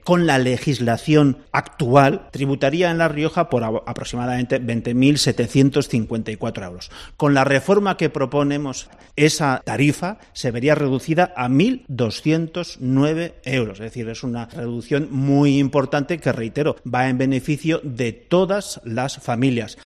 En COPE Rioja, el director general de Tributos ha puesto un ejemplo de lo que supondrá extender a padres e hijos y cónyuges la bonificación del Impuesto de Sucesiones y Donaciones
Hoy viernes, hemos hablado con Jesús Ángel Garrido, director general de Tributos, y nos ha puesto un ejemplo de lo que supondrá, en la práctica, esta bonificación para un hijo que, tras fallecer sus padres, recibe de ellos una vivienda valorada en 250.000 euros; otra vivienda en el pueblo, por 150.000 euros; fincas rústicas por valor de 50.000 euros; y ahorros en la cuenta corriente de 100.000 euros.